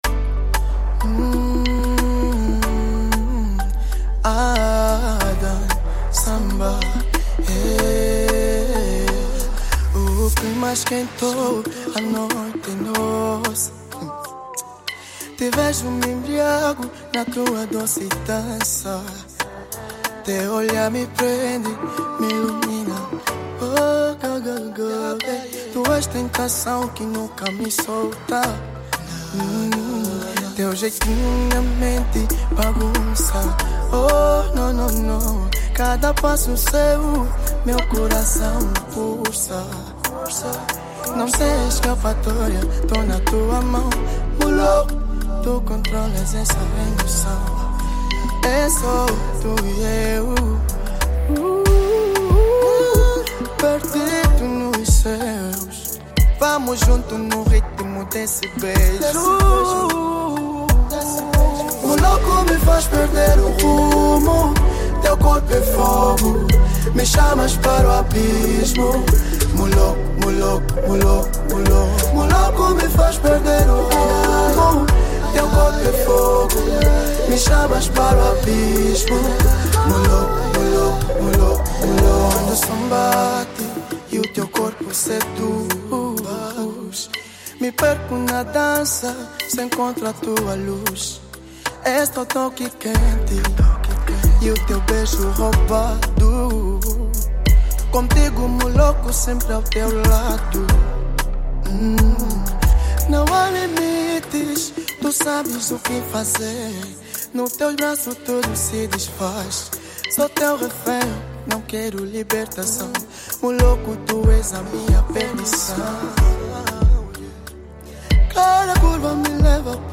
📌 Gênero: Zouk